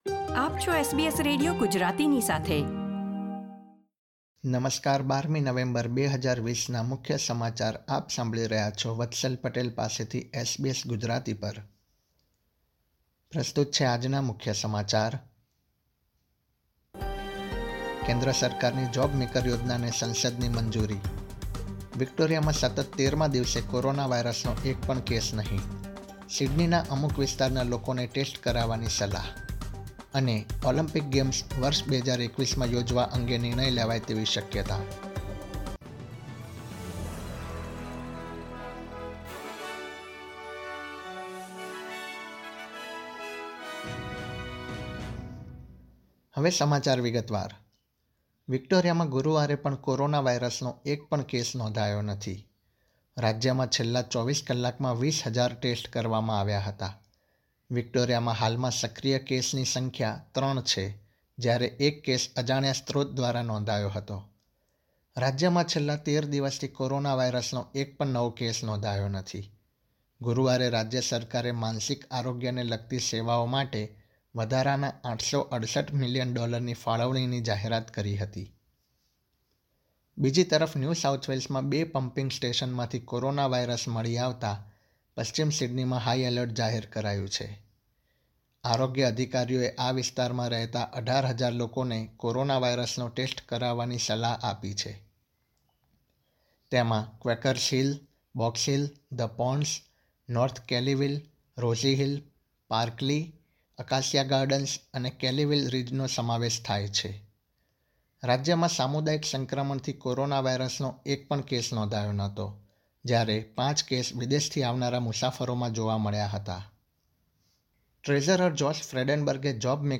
gujarati_1211_newsbulletin.mp3